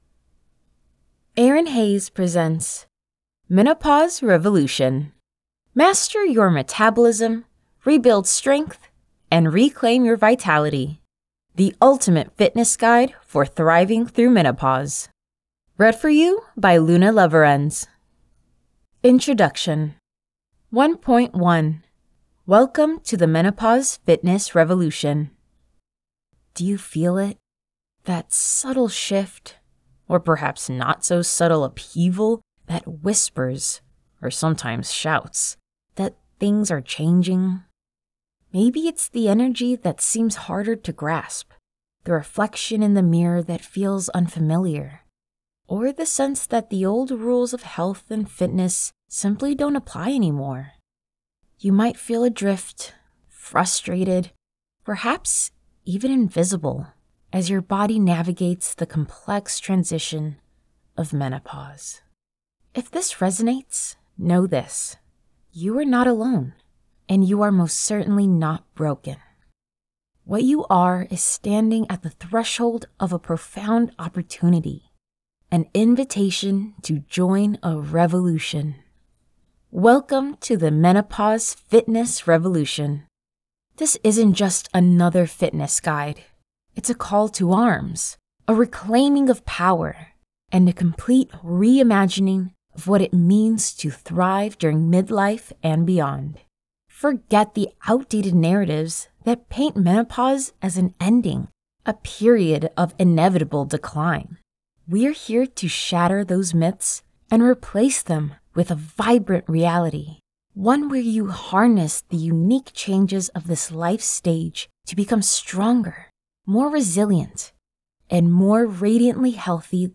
Menopause Revolution Audiobook Sample
American, Midwestern, Southern, British, Irish, German, Spanish